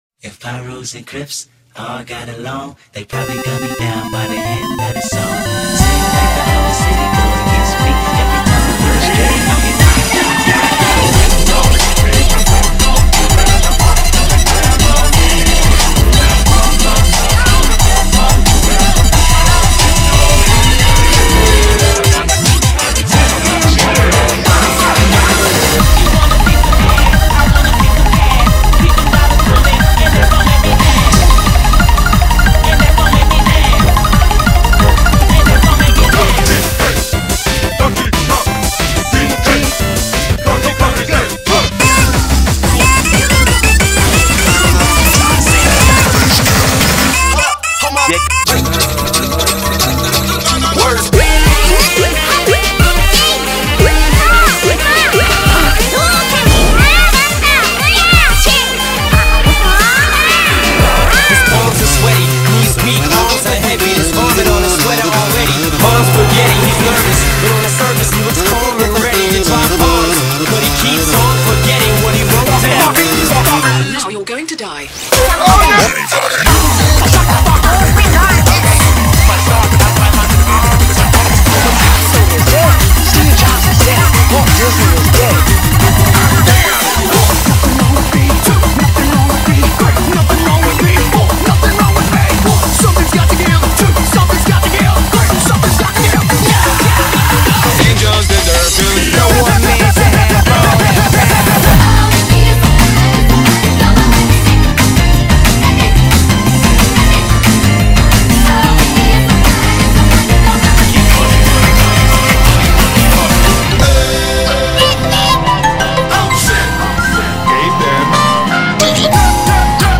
BPM180
Audio QualityPerfect (High Quality)
mashups/remixes